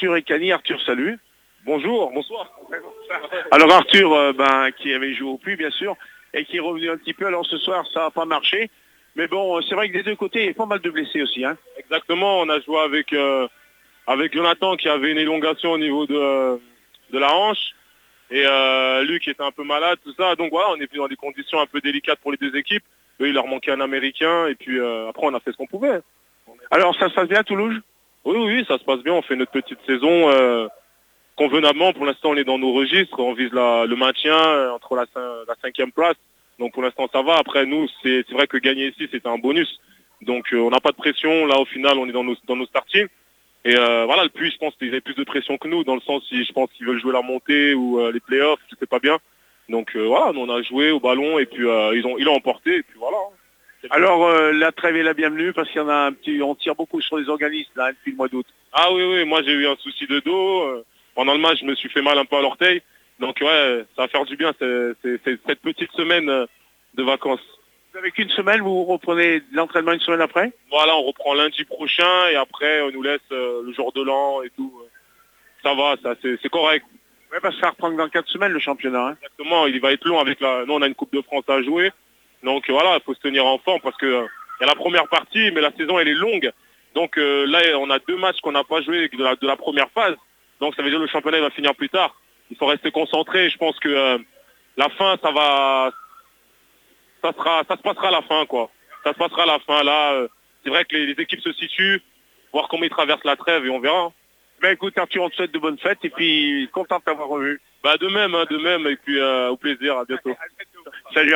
MATCH DE BASKET ASMB LE PUY-USA TOULOUGES 82-77 NATIONALE 2 APRES MATCH
REACTIONS APRES MATCH